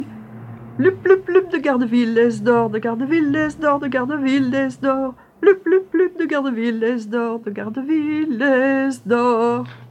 Genre : chant
Type : chanson d'enfants
Interprète(s) : Anonyme (femme)
Support : bande magnétique